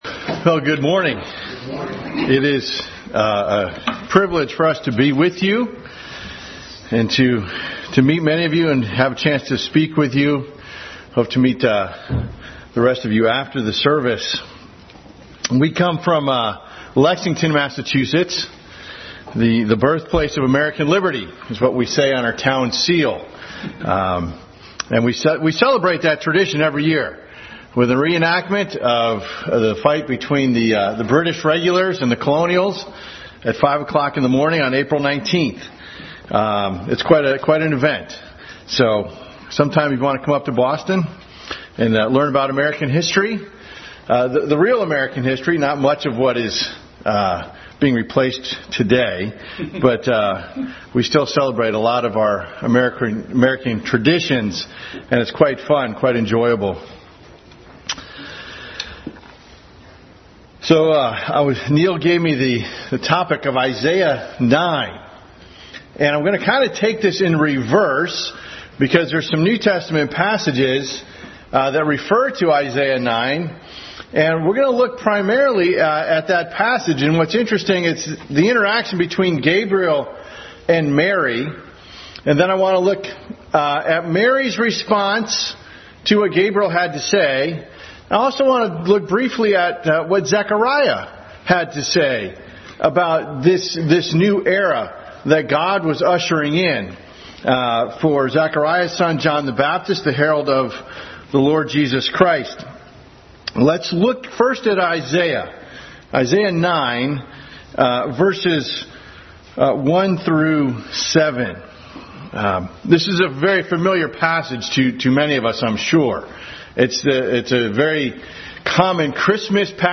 Adult Sunday School Class continued study of Jesus in Isaiah.
Service Type: Sunday School